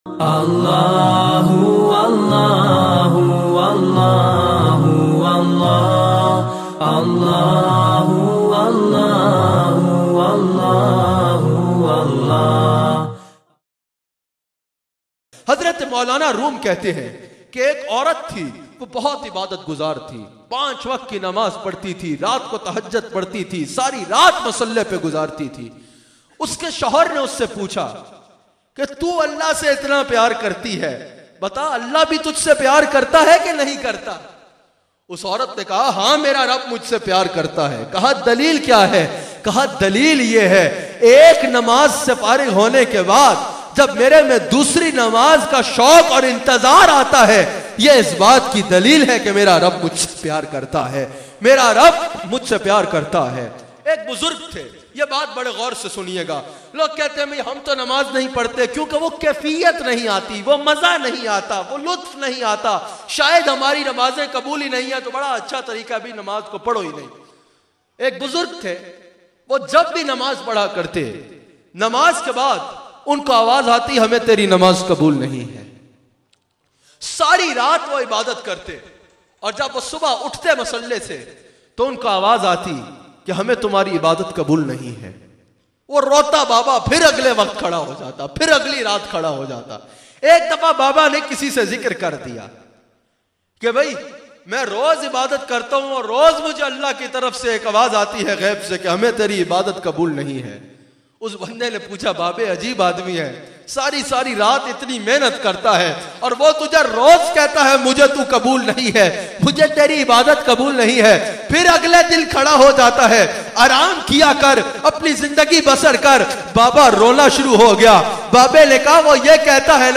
Orat ka waqia bayan mp3